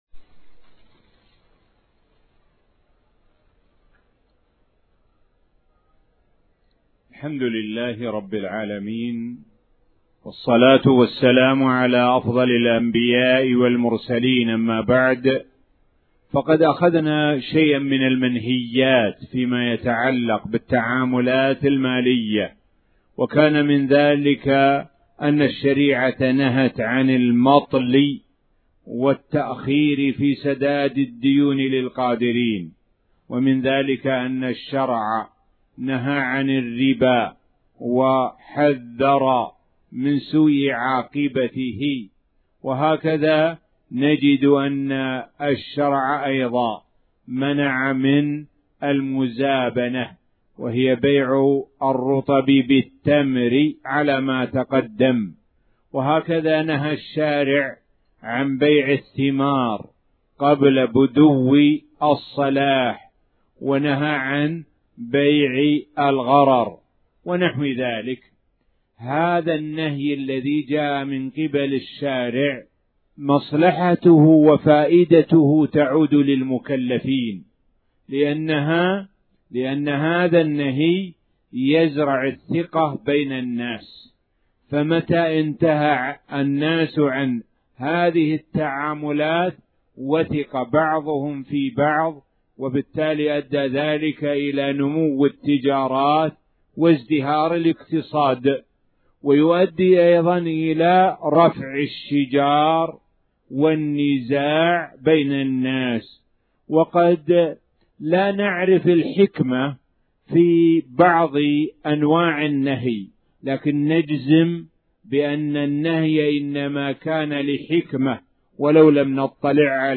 تاريخ النشر ٥ ذو الحجة ١٤٣٩ هـ المكان: المسجد الحرام الشيخ: معالي الشيخ د. سعد بن ناصر الشثري معالي الشيخ د. سعد بن ناصر الشثري بيع العرايا